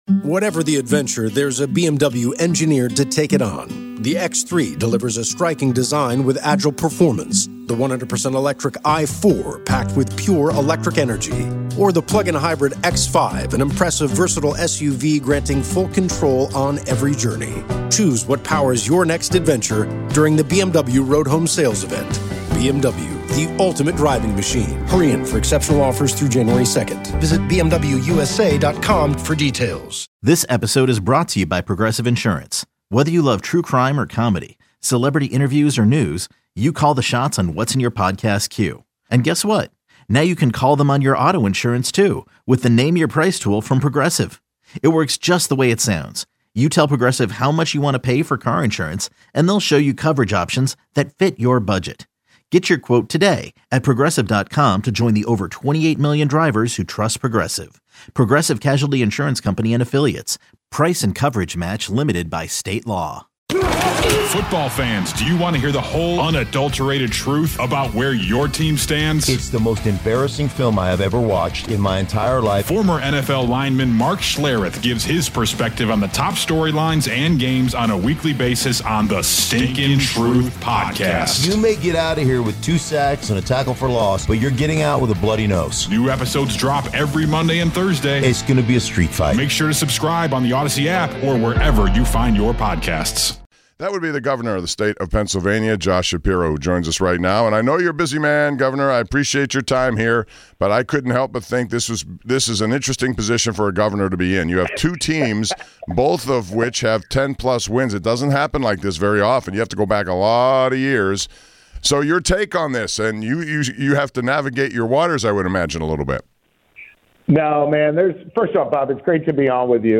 Governor Josh Shapiro joins the show! Josh calls this game the PA Bowl.